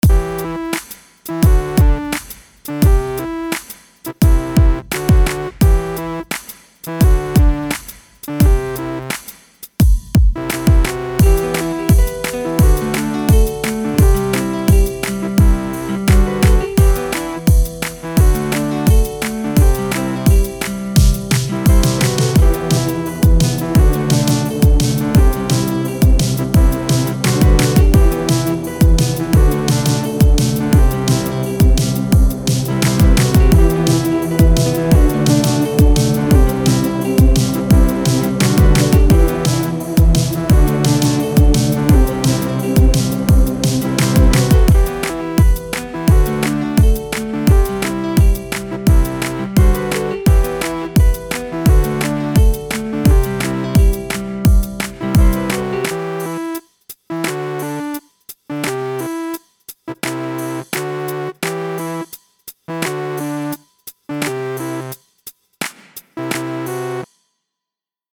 Remix / Dubstepsong
Habe eine Meldodie eingespielt auf 86 BPM. Hätte Lust auf harte Dubstep Drums und nochmal ein Arragement, wo ich evetuell was adden kann, wenn was produktives rauskommt.